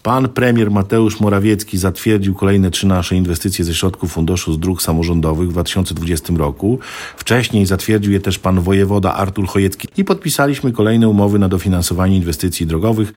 – W gminie Ełk inwestycje nie zwalniają tempa – mówi wójt Tomasz Osewski i zapowiada kolejne remonty lokalnych dróg.